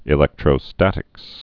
(ĭ-lĕktrō-stătĭks)